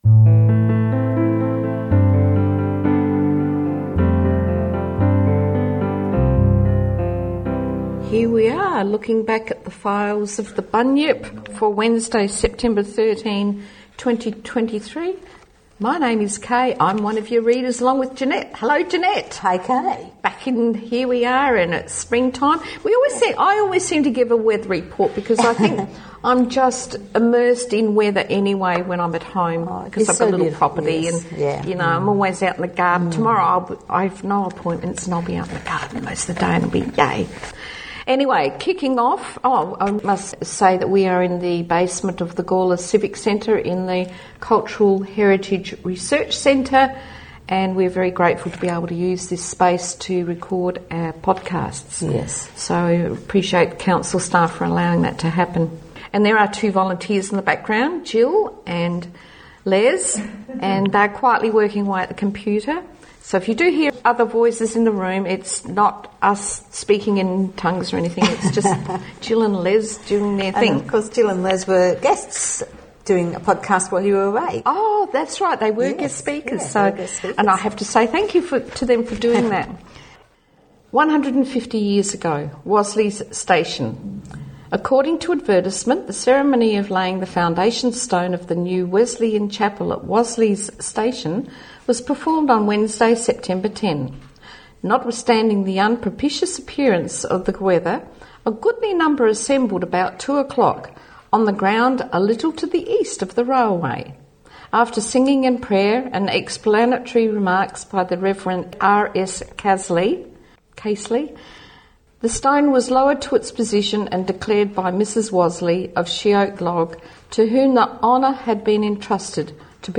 Opening and closing music